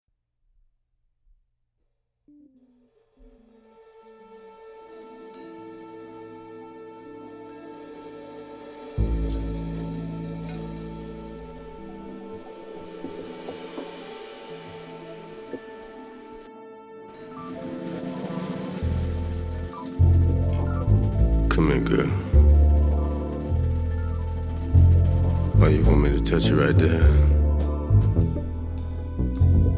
Hip Hop, Jazz, Funk, Experimental